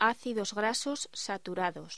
Locución: Ácidos grasos saturados